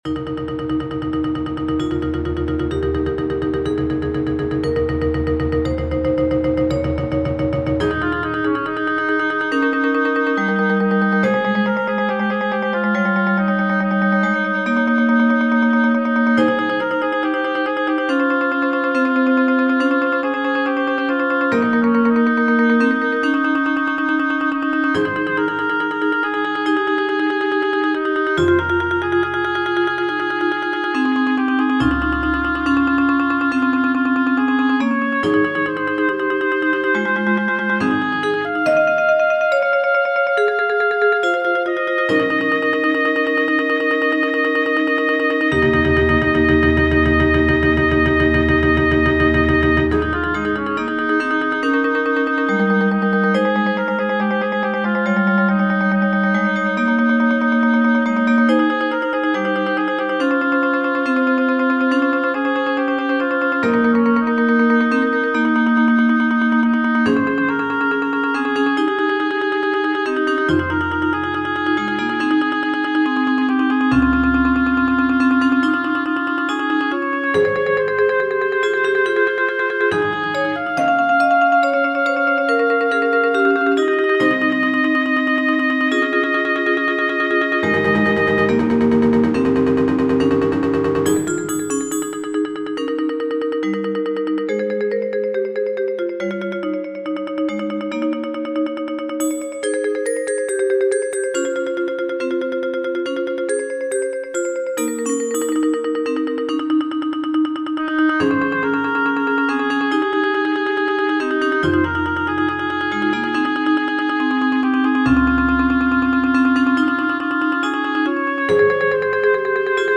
Mallet-Steelband Muziek
Klokkenspel Xylofoon Vibrafoon Marimba Hobo Trompet Timpani